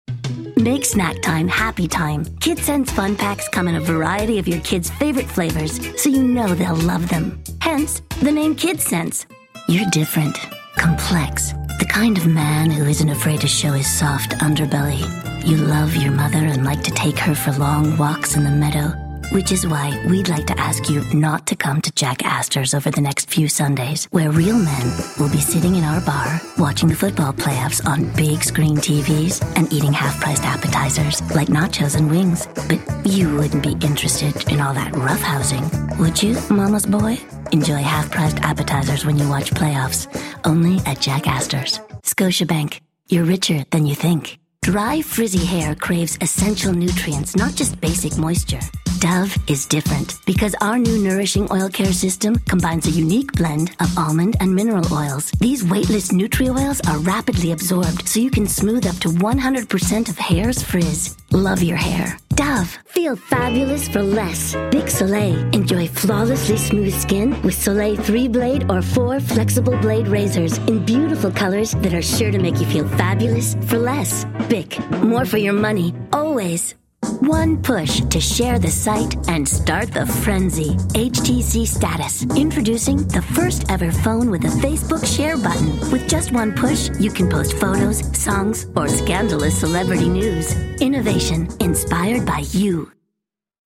Female Voice Actor Talent
Unique, very enthusiastic, real, feminine.
• Voice "Ages": Child, Teen, Young Adult